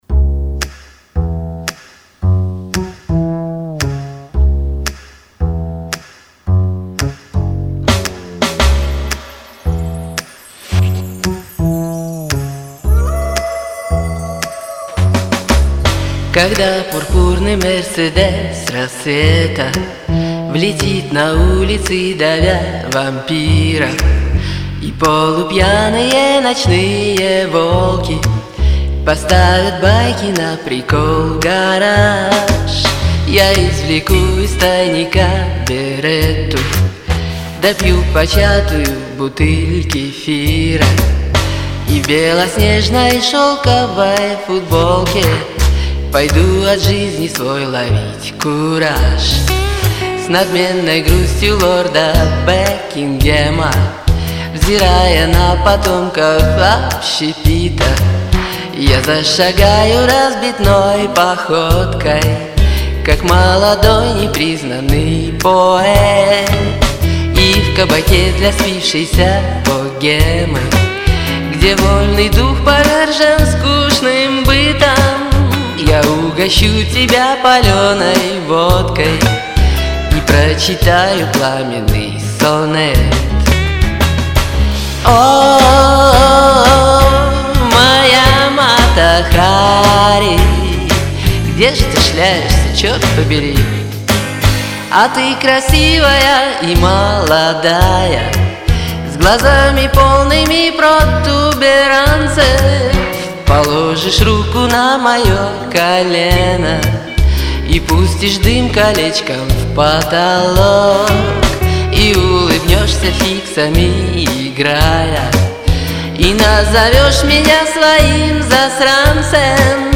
СТИЛЬНО И СМАЧНО СПЕТО!!!